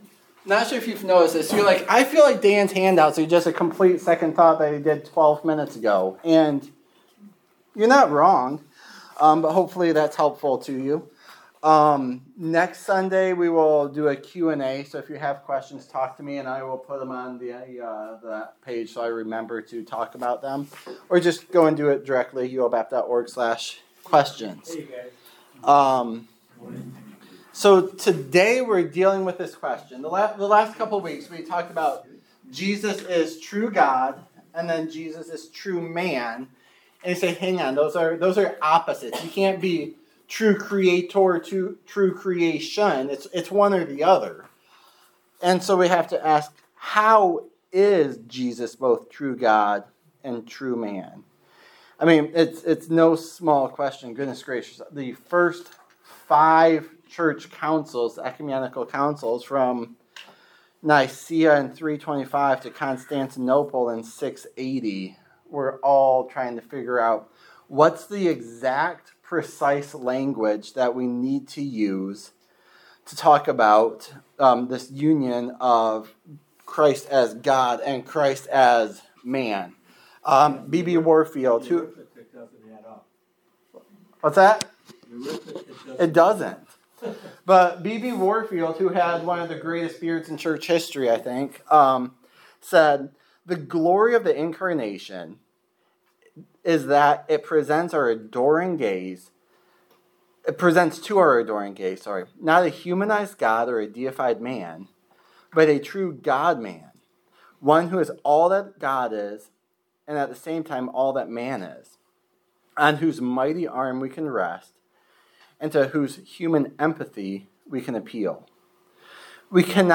Series: Systematic Theology Service Type: Sunday School